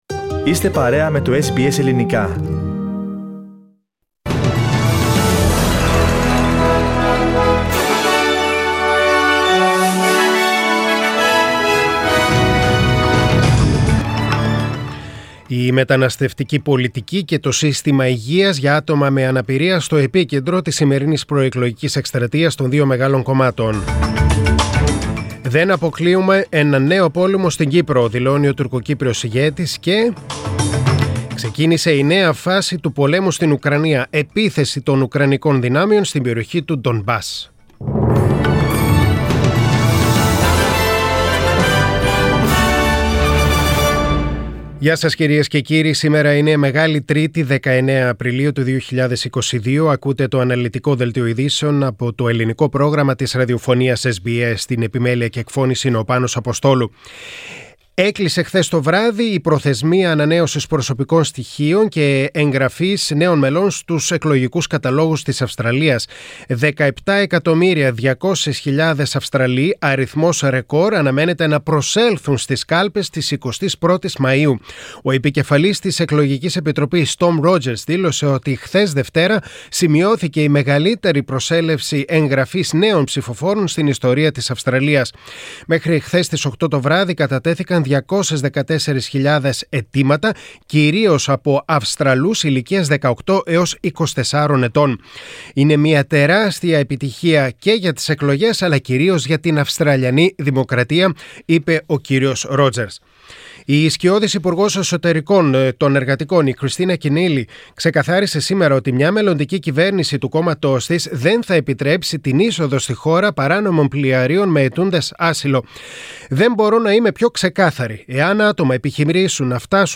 Δελτίο Ειδήσεων: Τρίτη 19.4.2022
Greek Main Bulletin. Source: SBS Greek